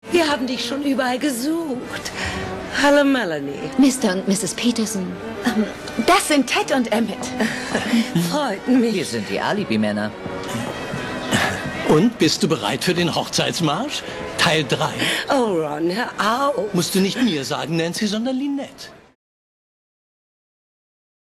Synchronschauspielerin
Hier ein paar SPRACHPROBEN